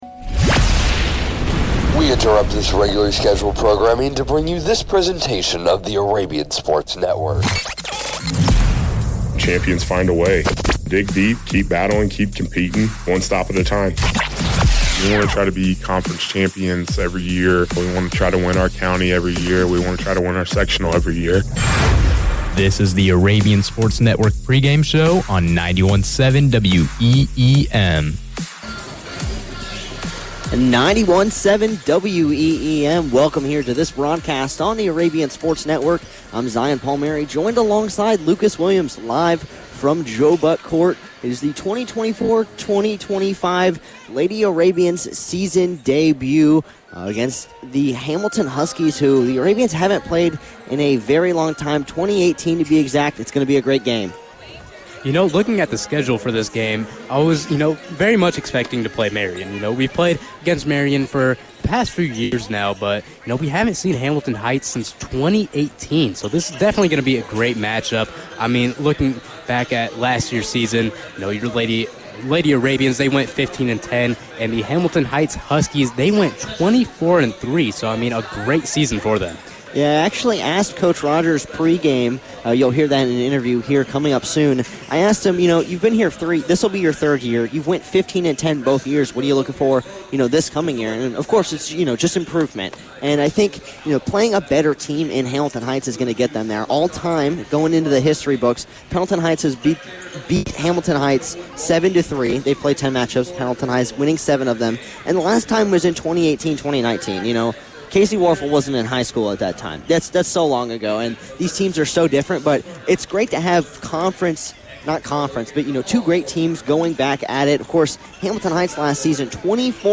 Varsity Girls Basketball Broadcast Replay Pendleton Heights vs. Hamiliton Heights 11-7-24